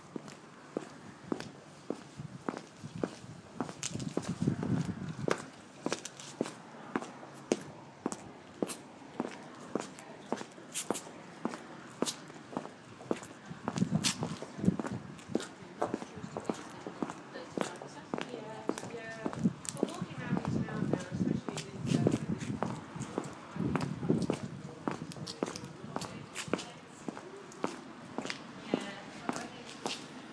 Sound of footsteps walking on wet cobbles